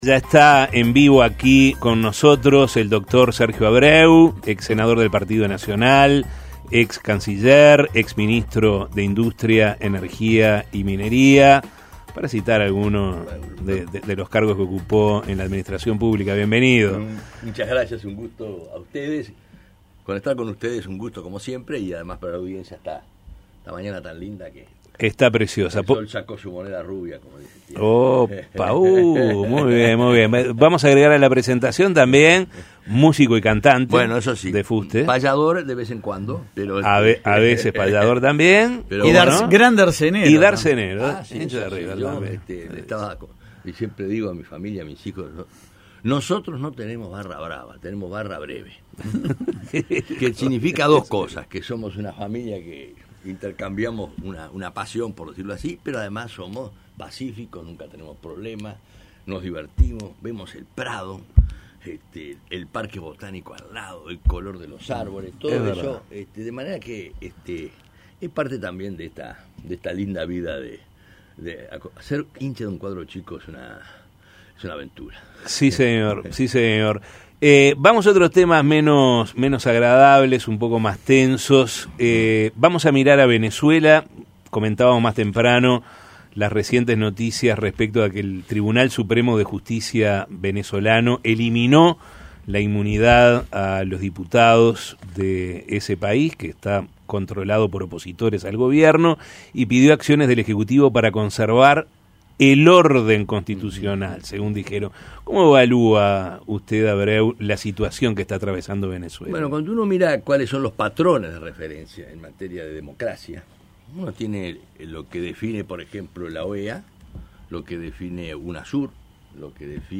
Sergio Abreu, excanciller de la República, visitó este jueves La Mañana de El Espectador.
Escuche la entrevista de La Mañana: